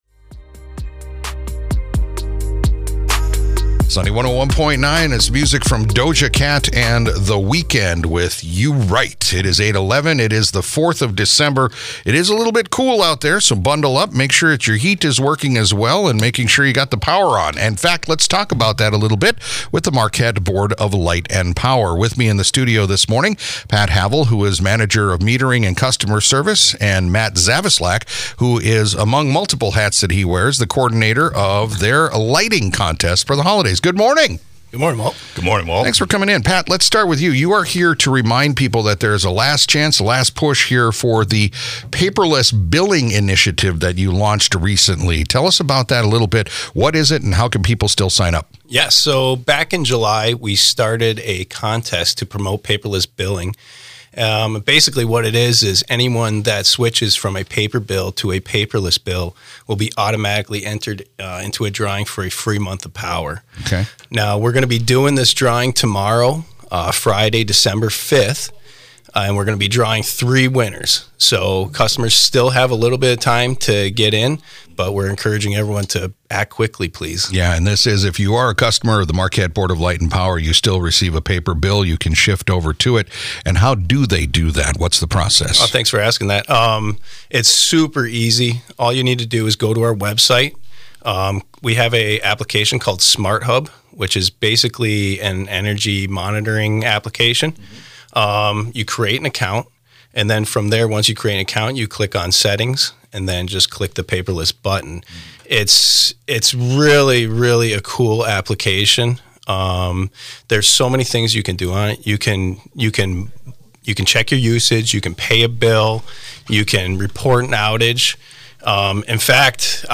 spoke about the programs